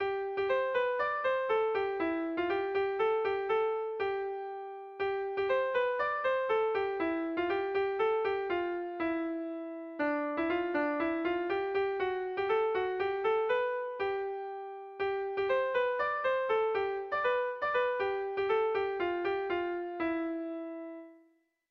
Sentimenduzkoa
A1A2BA3